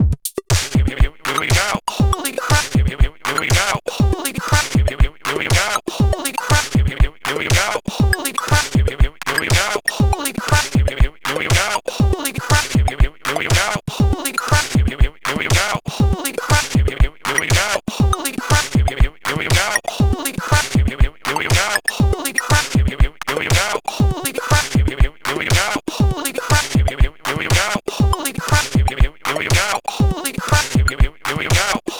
ご用意したのは、ステレオの一般的なループ音源と、POシリーズに同期をして楽しめるクリック入りのシンクモードのループ音源です。
BPM 120（DISCOモード）